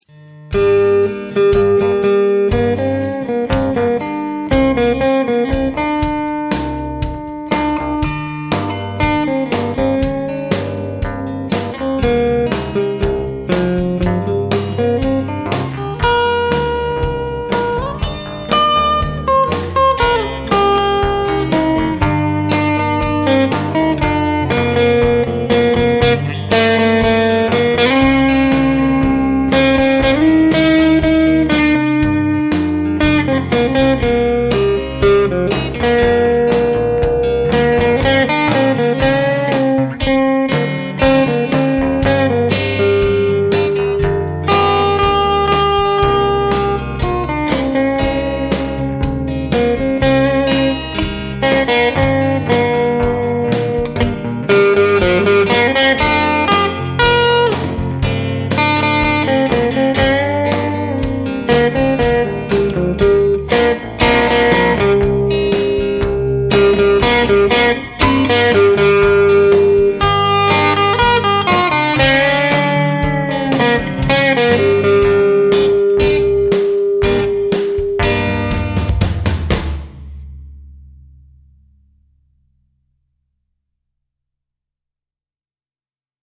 Tämmöisen äänittelin kokeeksi usb:n kautta suoraan Logiciin
Siinä on kitarana American deluxe stratocaster ja Fenderin akustinen basso.
huomasin tässä että mun klarinetinsoitto kuulostaa aika luonnonmukaiselta... lähinnä niinkuin sorsalta